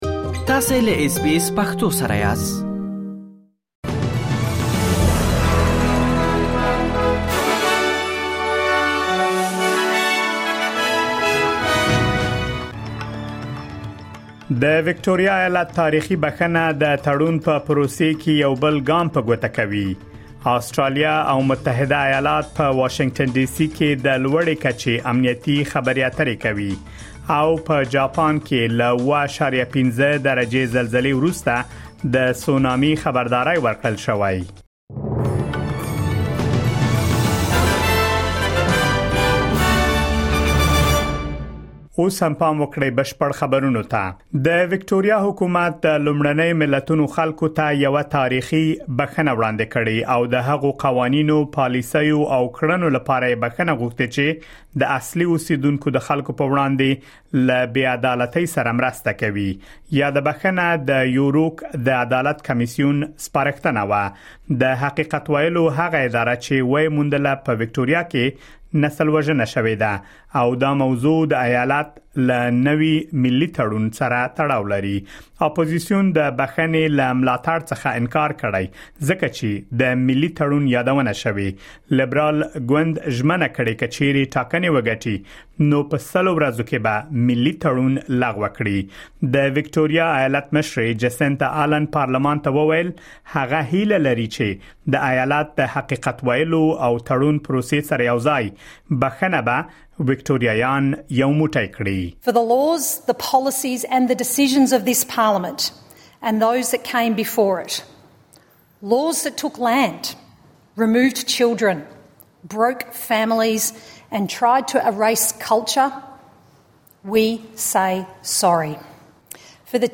د آسټراليا او نړۍ مهم خبرونه
د اس بي اس پښتو د نن ورځې لنډ خبرونه دلته واورئ.